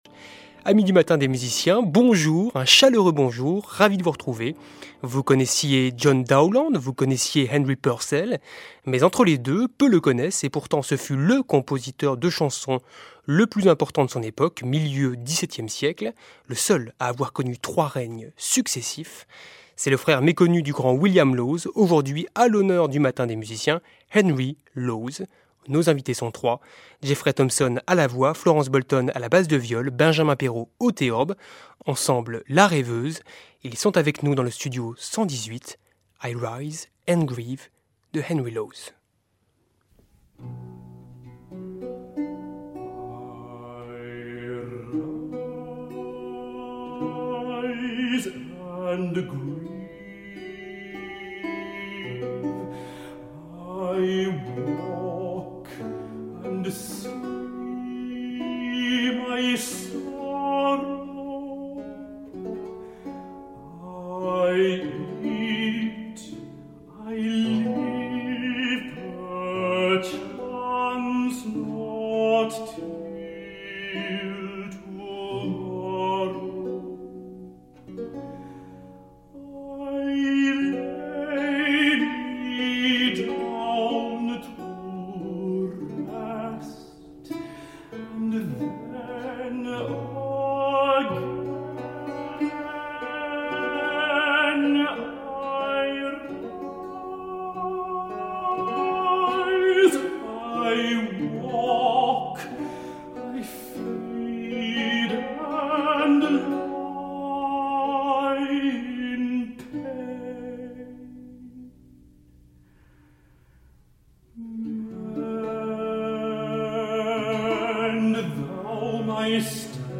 Le baroque c’est aussi du rock et pourquoi la musique ancienne, ça décoiffe.
Musique ravageuse et déprimante
haute-contre